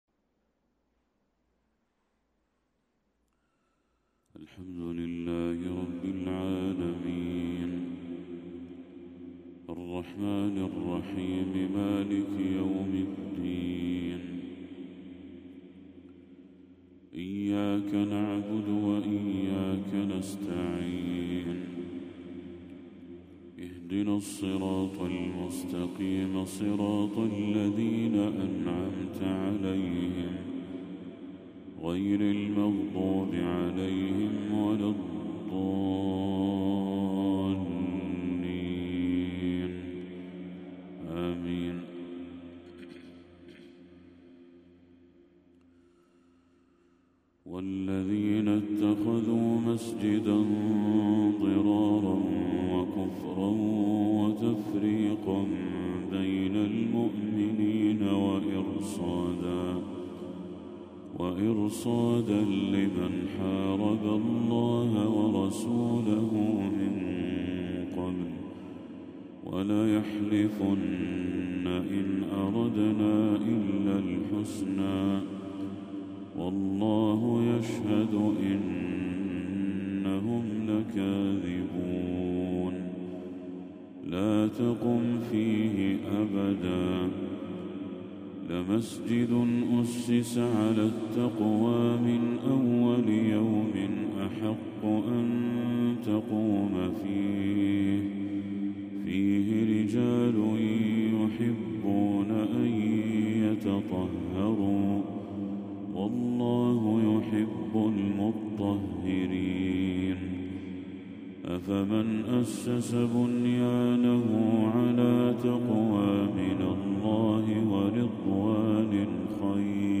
تلاوة أخاذة من سورة التوبة للشيخ بدر التركي | فجر 22 ربيع الأول 1446هـ > 1446هـ > تلاوات الشيخ بدر التركي > المزيد - تلاوات الحرمين